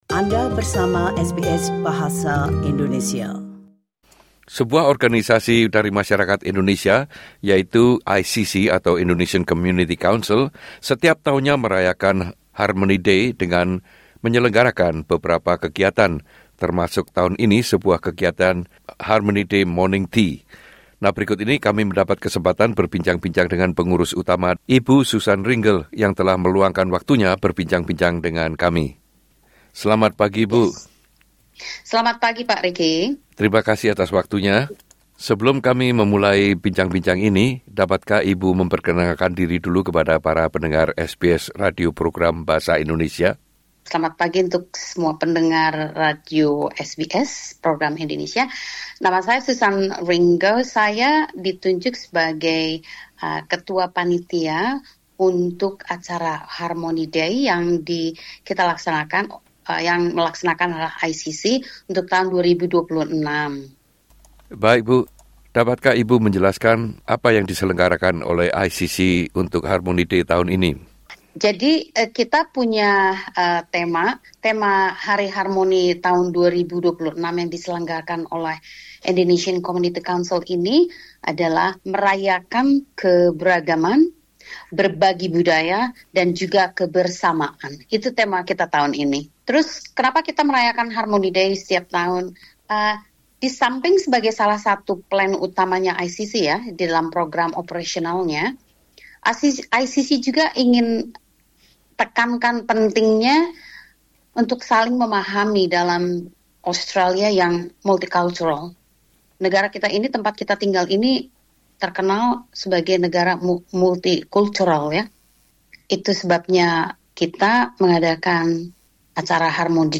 In the interview session